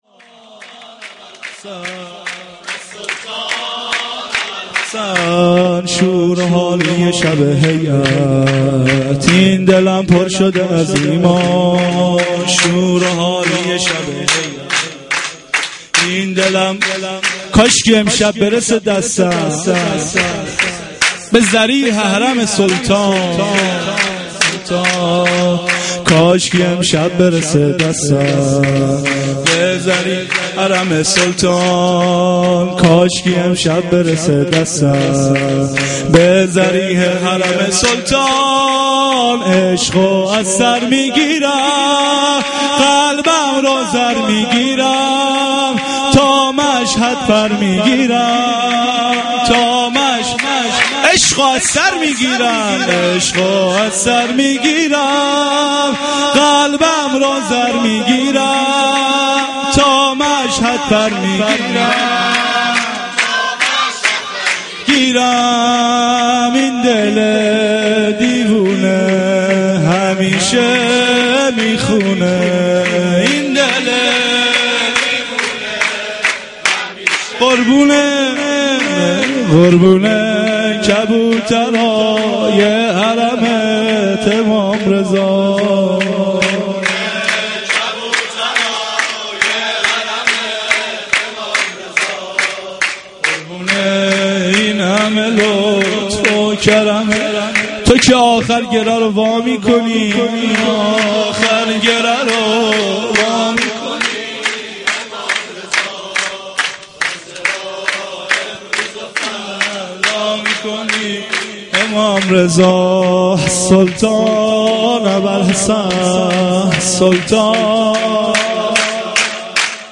Veladate Emam Reza 93 -Sorood Part 4.mp3